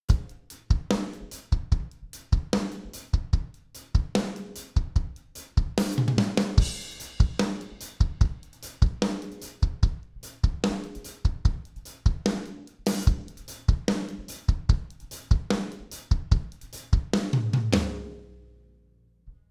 No UV EQ applied to audio
UV EQ Drums Dry.wav